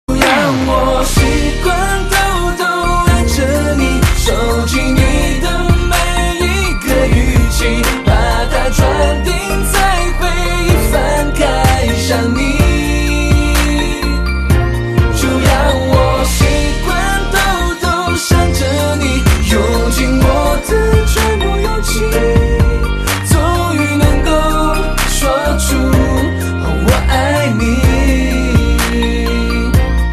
M4R铃声, MP3铃声, 华语歌曲 118 首发日期：2018-05-15 08:41 星期二